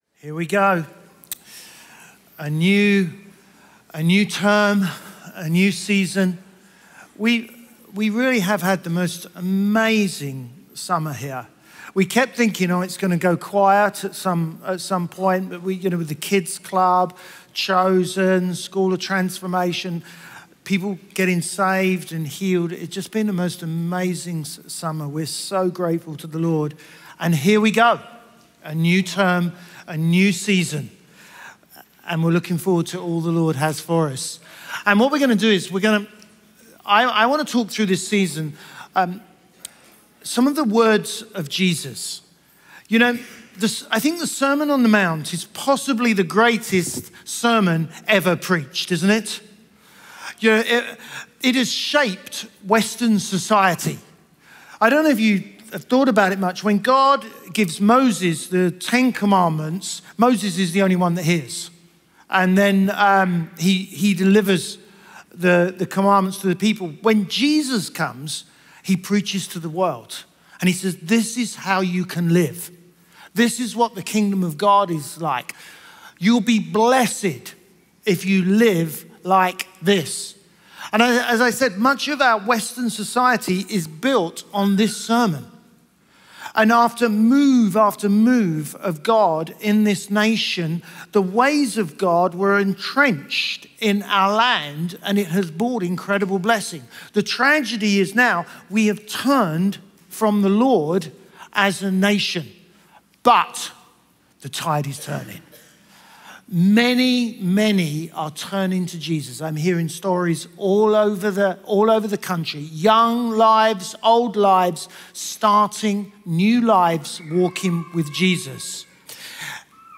Chroma Church - Sunday Sermon Salt and Light